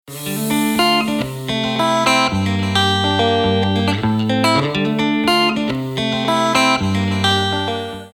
• Качество: 320, Stereo
гитара
спокойные
без слов
Melodic
romantic
guitar